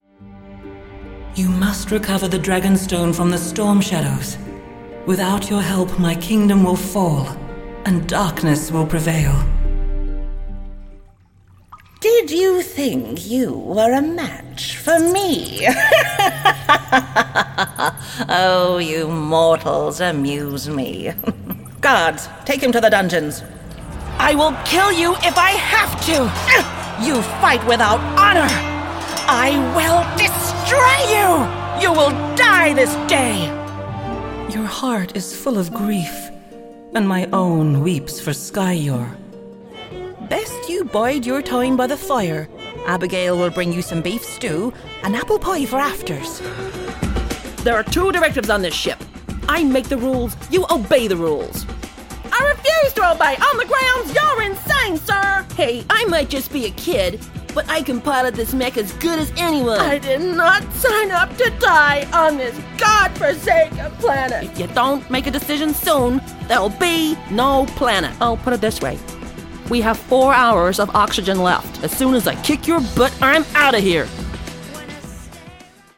Female
English (Australian)
Adult (30-50), Older Sound (50+)
A warm and resonant voice. An authoritative tone or the authenticity of an approachable friend.
With a neutral international accent I can deliver a natural Australian, UK, or US accent.
Video Games Fantasy And Sci Fi